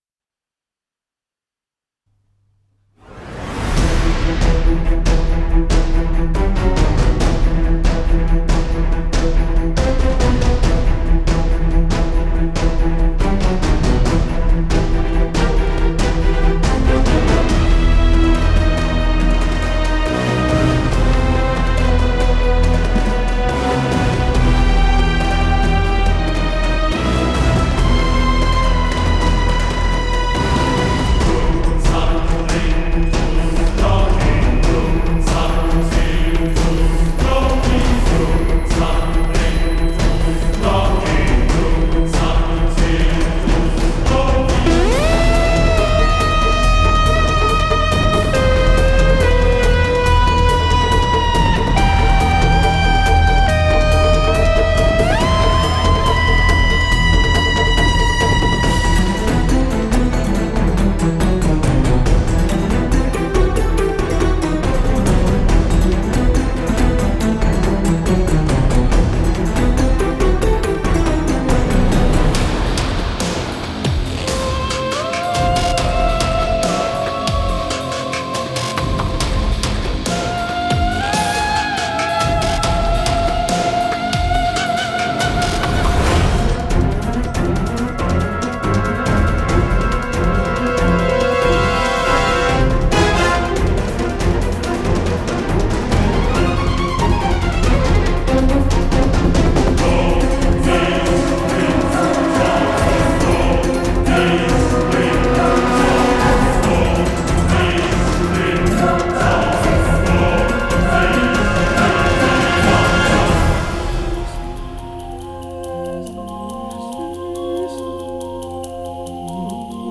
Музло из битвы с русичем
РУССКАЯ НАРОДНАЯ БЛАТНАЯ ХОРОВОДНАЯ